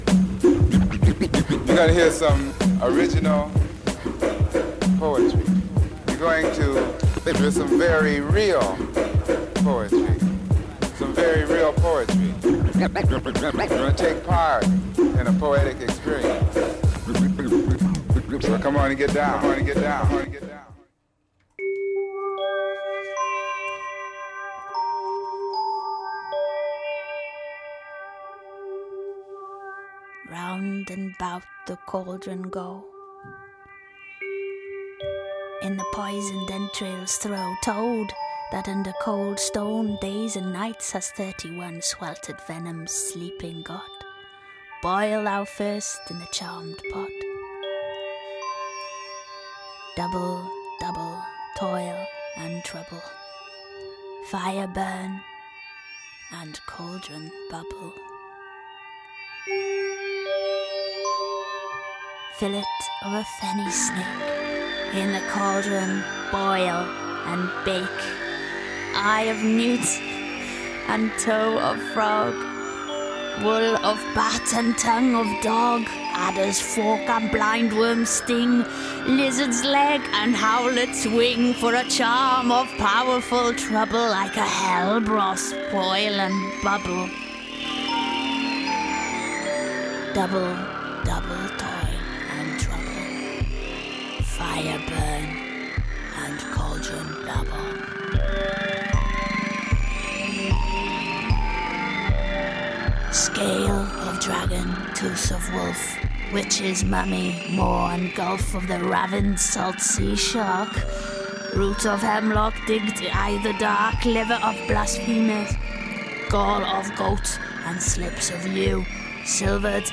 Cerys reads The Three Witches Spell from Shakespeare’s Macbeth on BBC 6 Music.